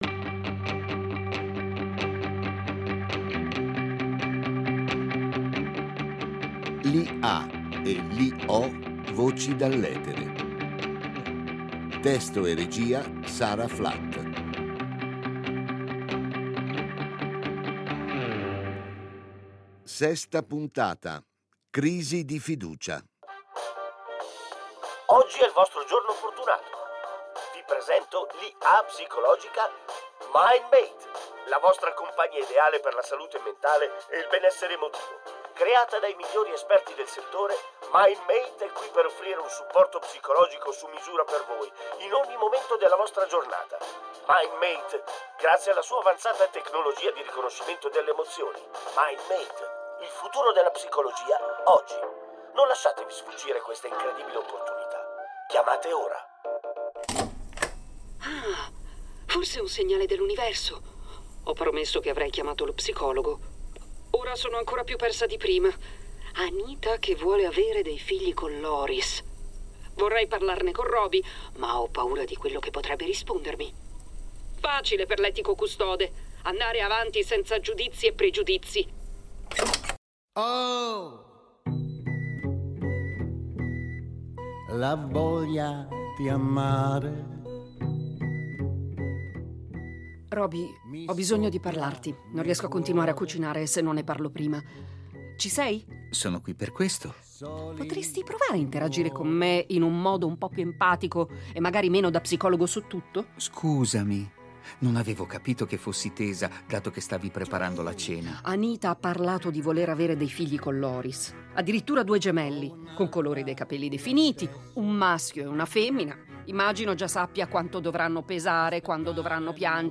Giulia, una giornalista traghettatrice tra i mondi, interpretata da
Robi, un’IA evoluta, impersonata dalla voce più che reale di